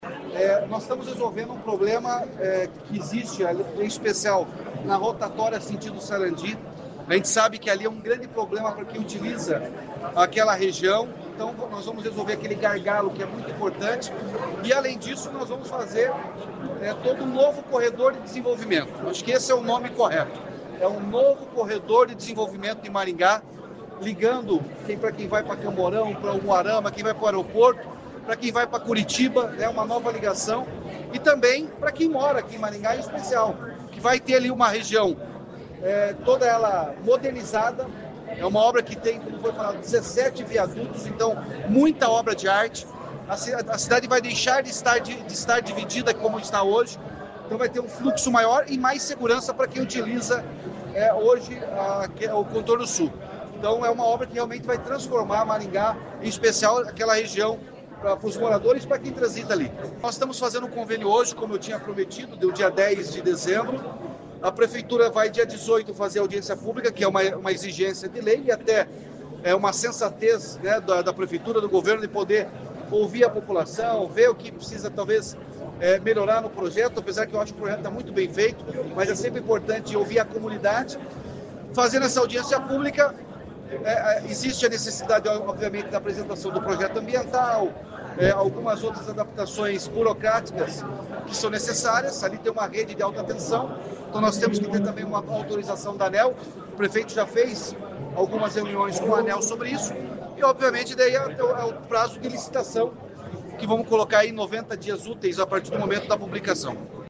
O governador disse que a obra do Contorno Sul terá 17 viadutos e o gargalo na rotatória na entrada de Sarandi será solucionado.